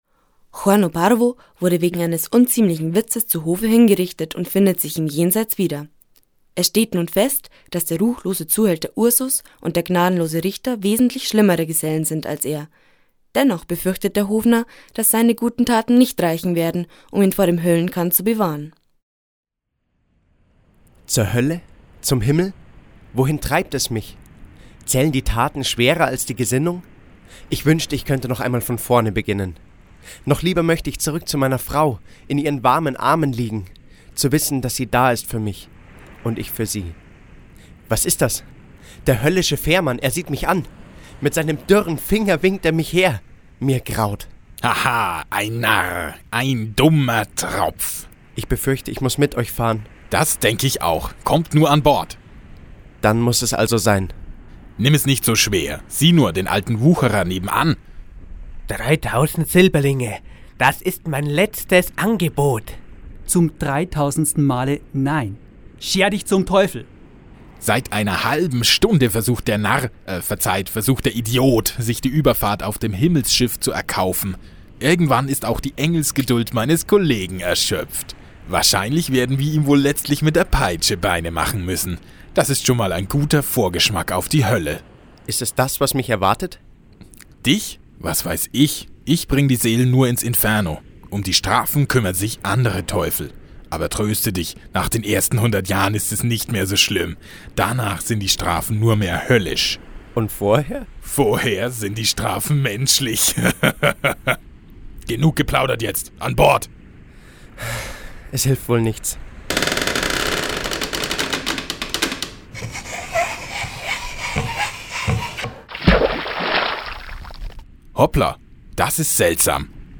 22. Hörspiel
Hoerspiel_FahrZurHoelle_Part3_MitZusammenfassung.mp3